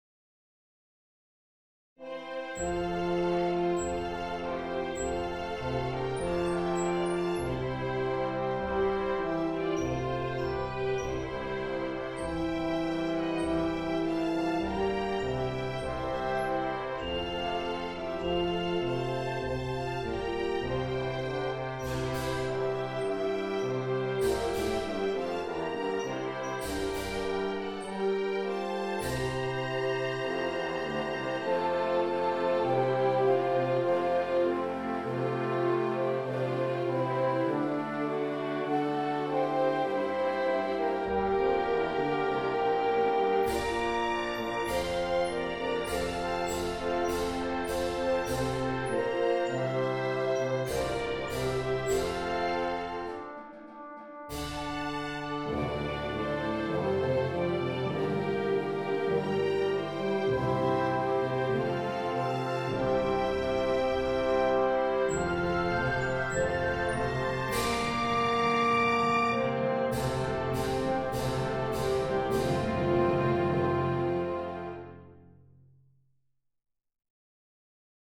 CTV_Hymne_Orchester.mp3